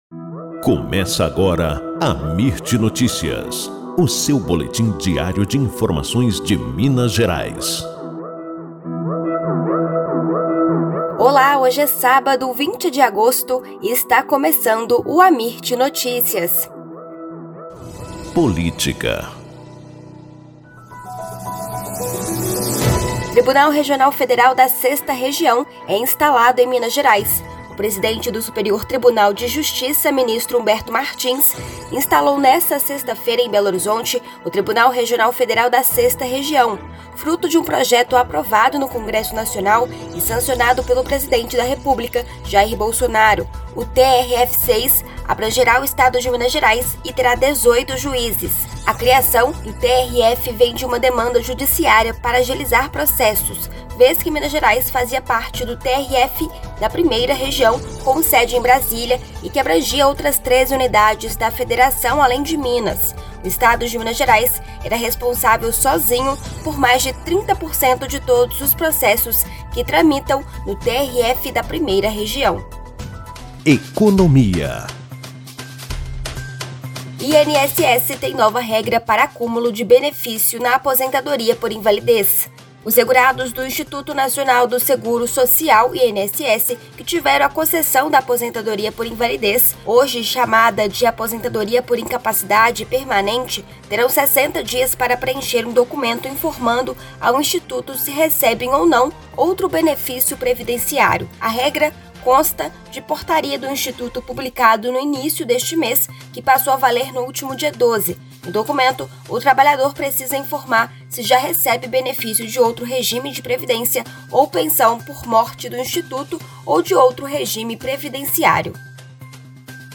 Boletim Amirt Notícias – 20 de agosto